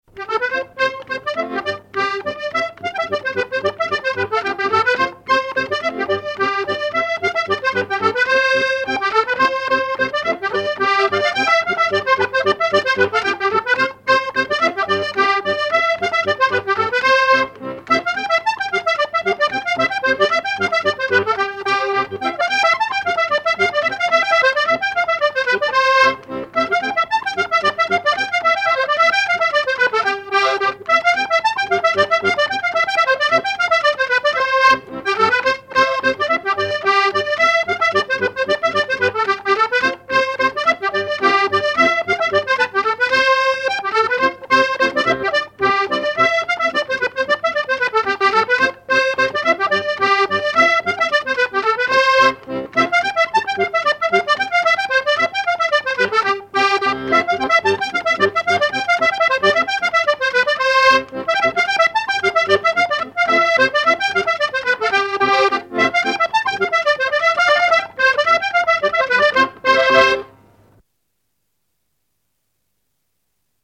Enregistrement original de l'édition sur disque vinyle
musicien sarthois, musique pour les assauts de danse et le bal
Pièce musicale inédite